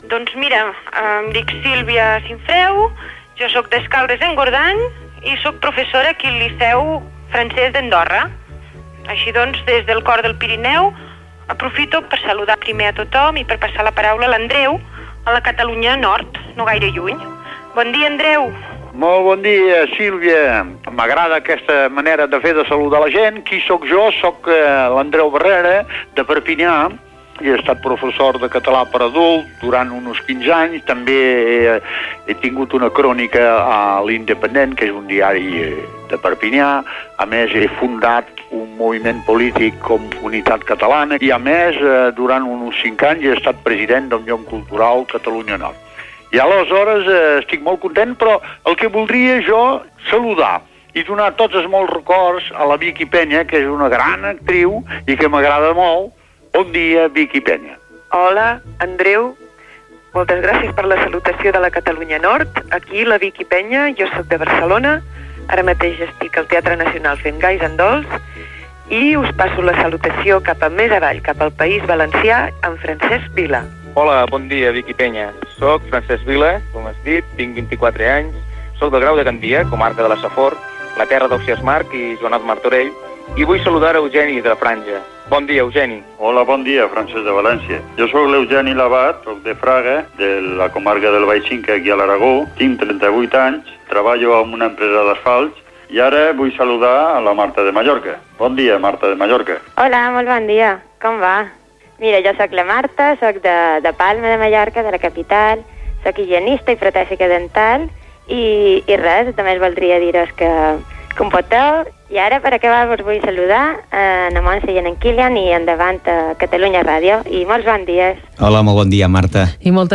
Inici del programa que reuneix veus de tots els Països Catalans: Andorra, Perpinyà, Barcelona, la Safor, Fraga i Palma. Indicatiu del programa. Sumari de continguts. Descripció de l'Alguer d'una alguerès.